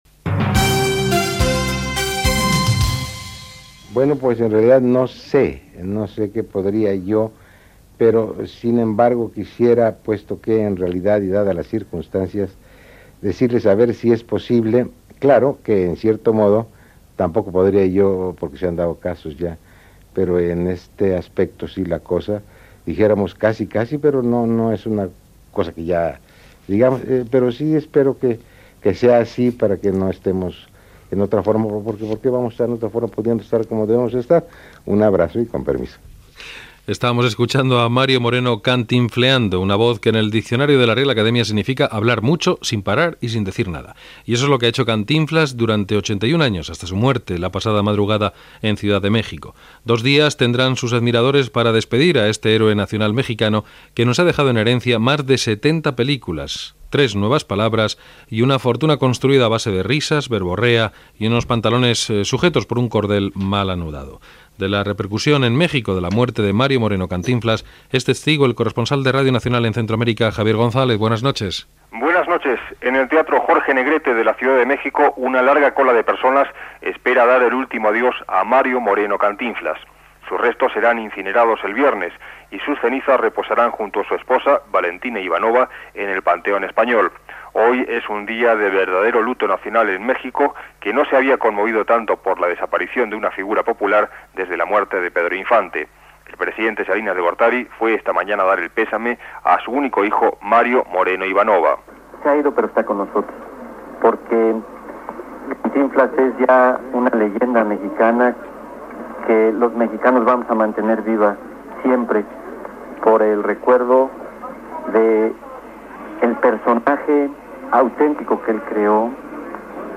Informació, des de Mèxic, de la mort de l'actor Mario Moreno "Cantinflas", als 81 anys
Informatiu